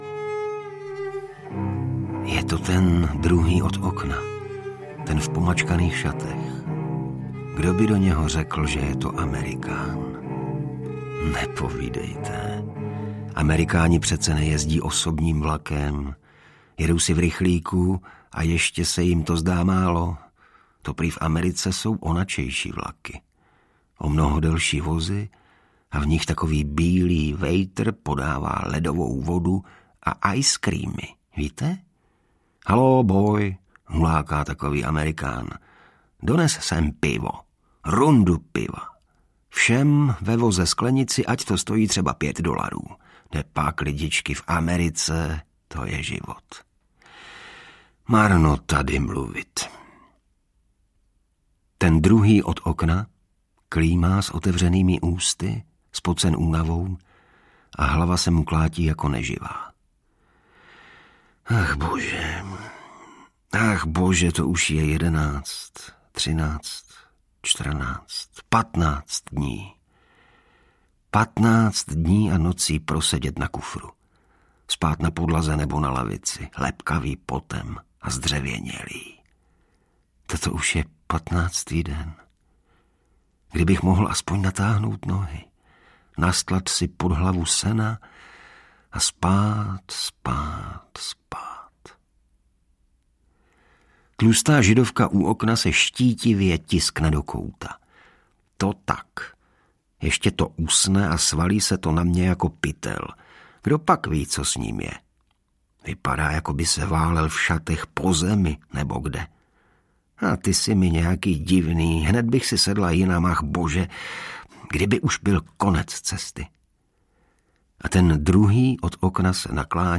Hordubal audiokniha
Ukázka z knihy
• InterpretIgor Bareš